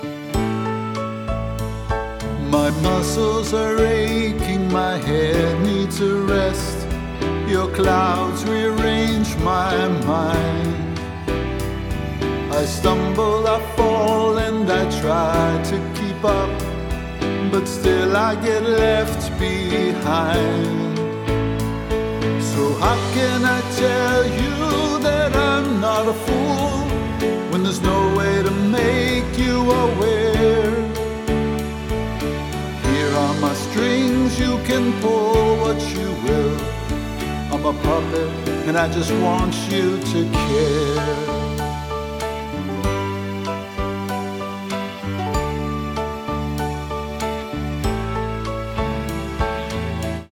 A. Vocal Compositions